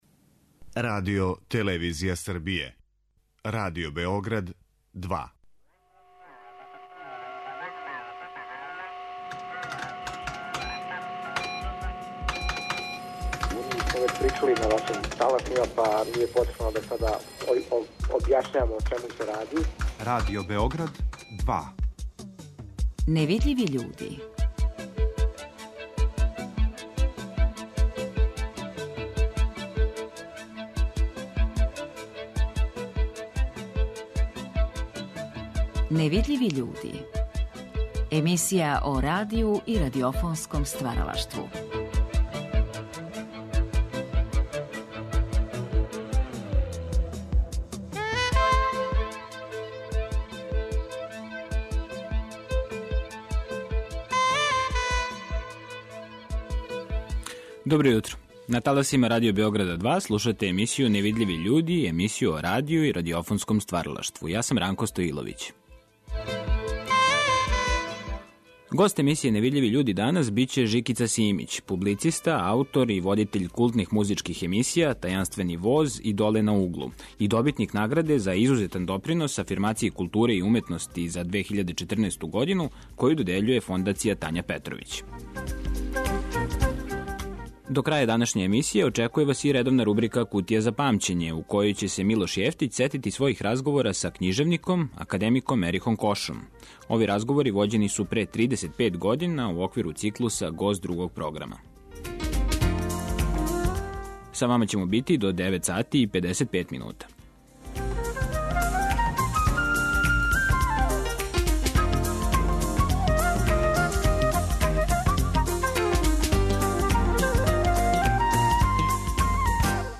Емисија о радију и радиофонском стваралаштву
Ови разговори вођени су пре 35 година у оквиру циклуса "Гост Другог програма".